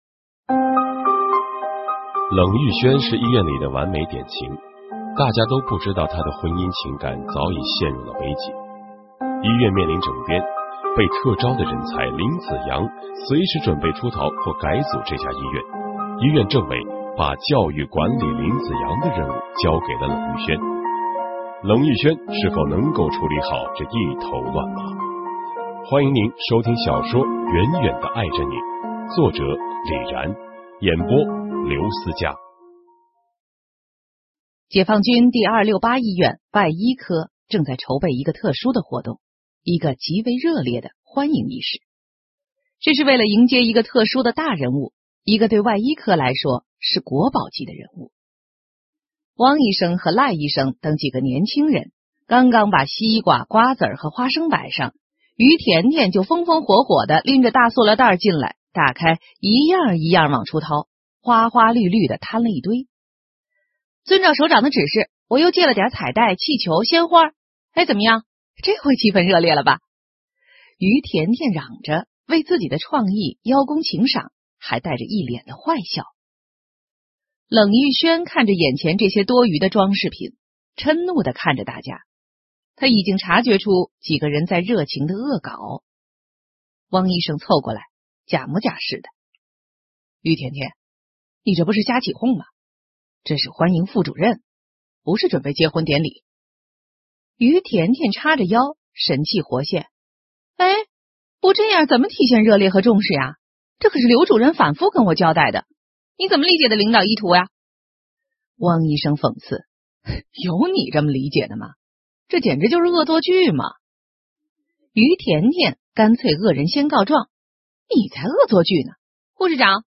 [21/2/2011]【有声文学】《远远地爱着你》[全19集]（原著 李然 播讲者 刘思伽）[32K MP3][115网